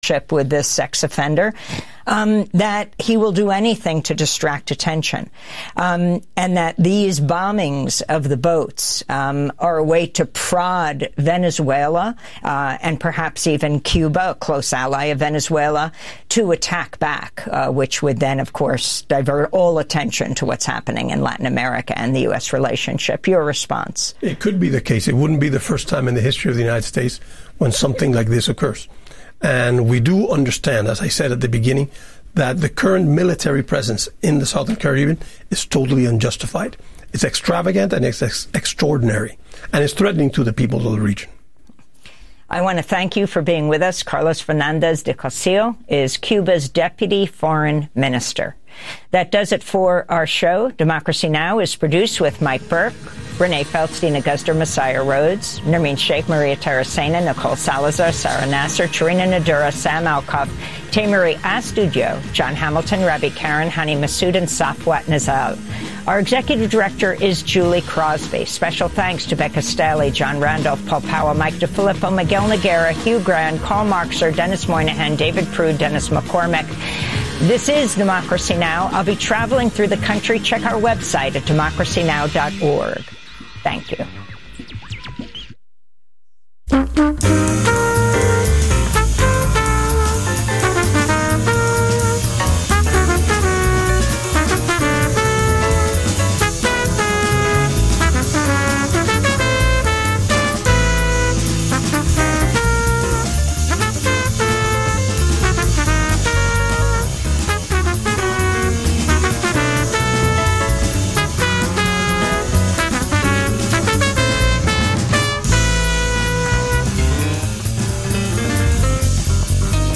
Evening News on 09/26/25
Non-corporate, community-powered, local, national and international news